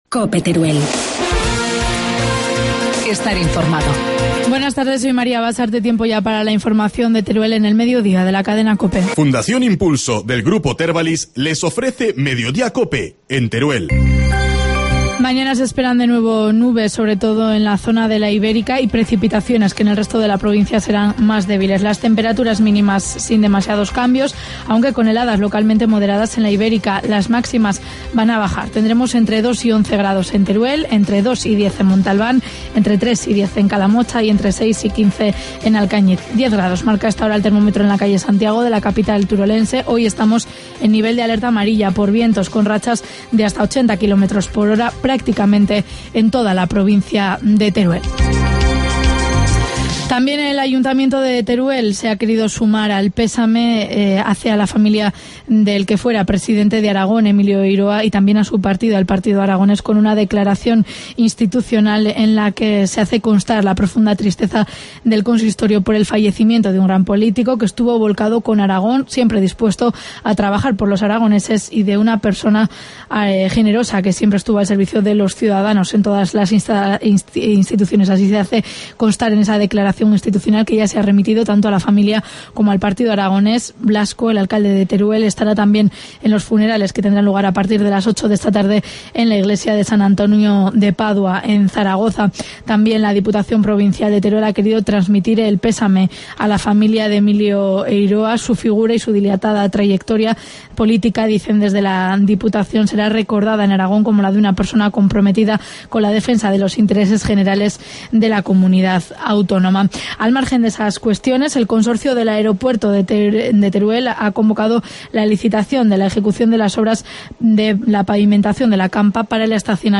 Informativo matinal, lunes 11 de marzo